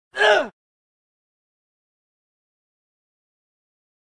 gag1.ogg